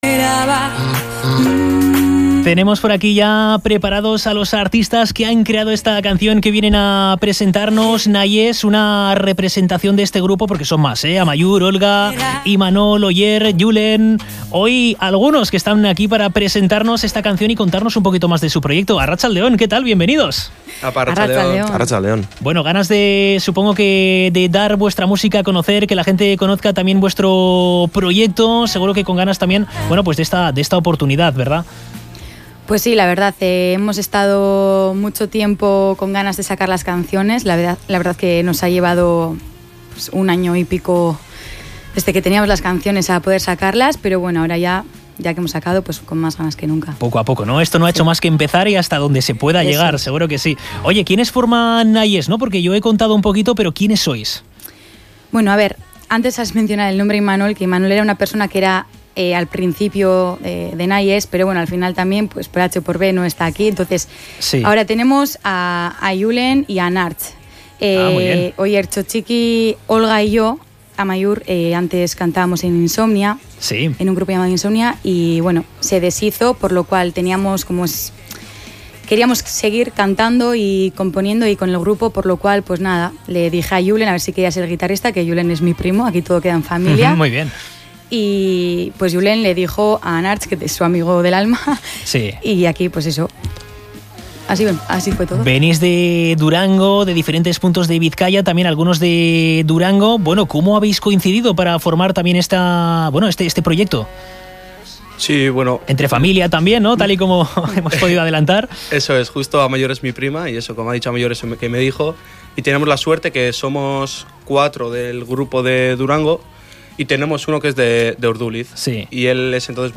Escucha la entrevista a Nahiez en la que charlamos sobre su nuevo comienzo, su primer sencillo y sus proyectos de futuro